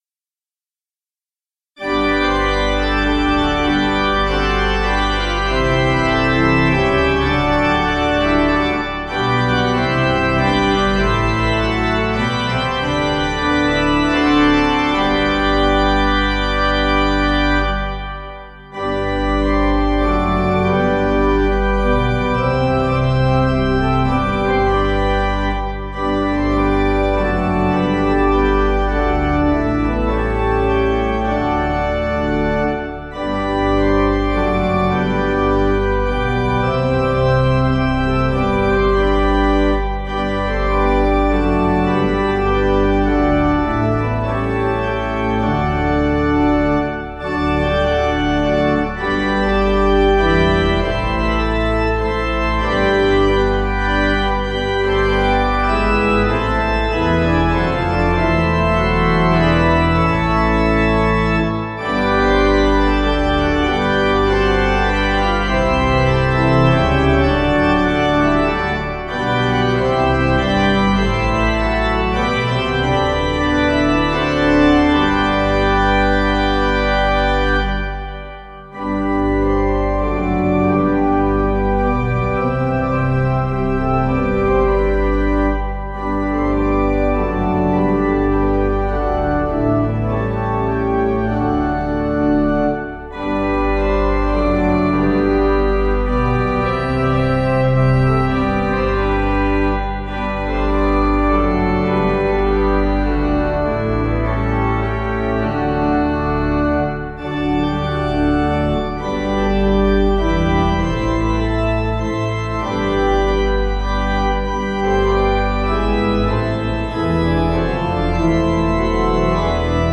8.7.8.7.D
Organ